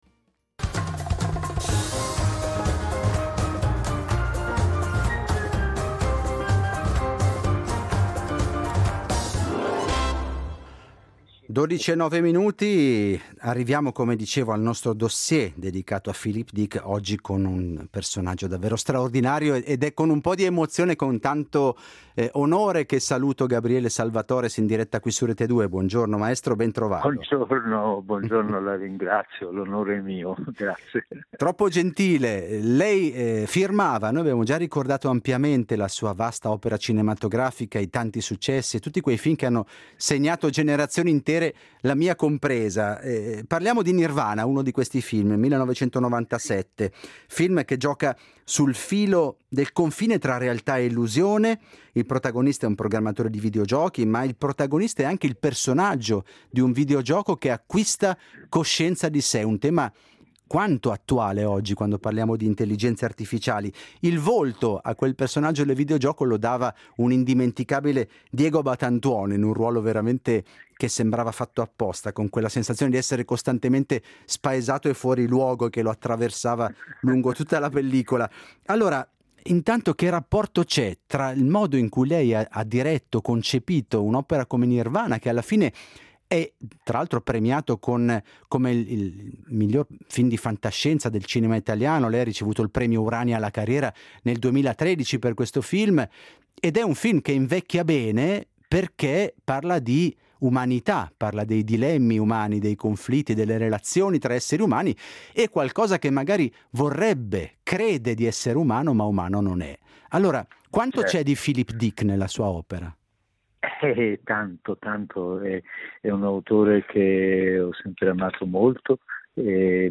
Da Dick a Nirvana: intervista a Gabriele Salvatores (2./5)